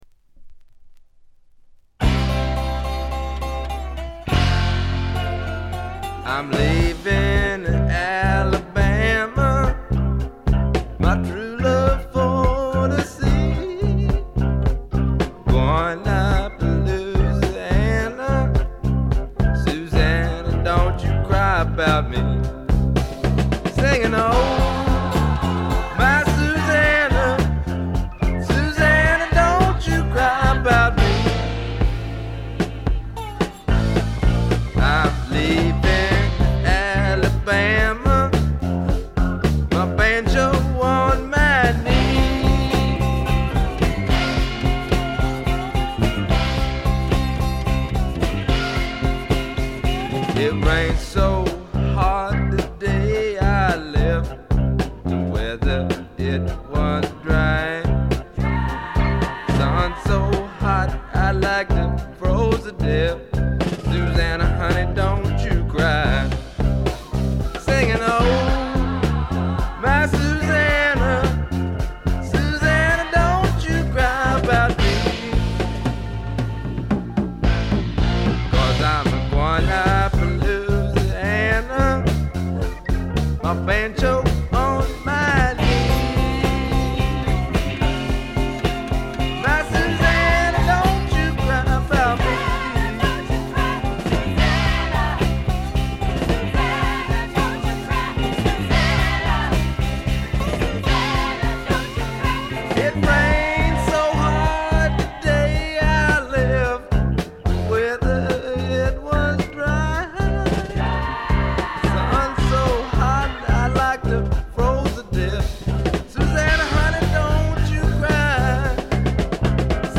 軽微なチリプチがほんの少し。
いうまでもなく米国スワンプ基本中の基本。
試聴曲は現品からの取り込み音源です。